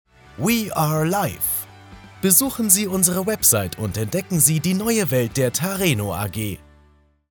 Professioneller Sprecher & Moderator
Mein hauseigenes Studio in Broadcast-Qualität sorgt für exzellente Ergebnisse bei vielfältigen Projekten.